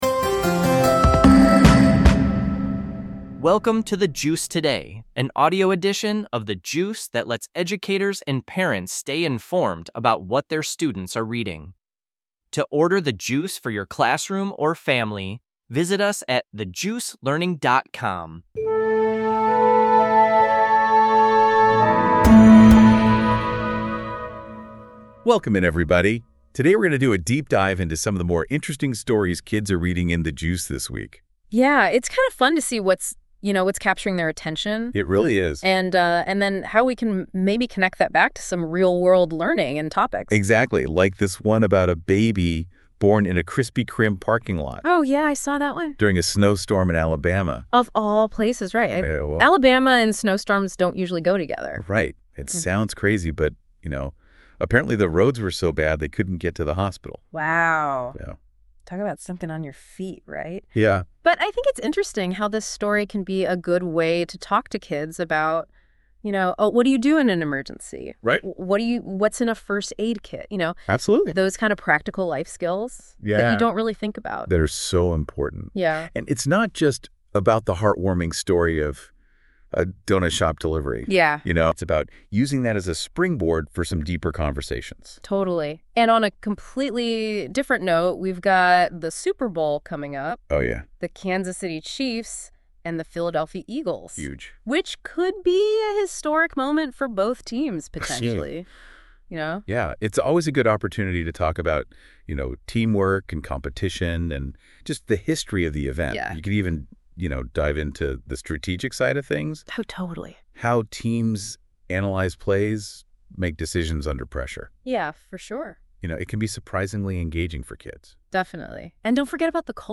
This podcast is produced by AI based on the content of a specific episode of The Juice.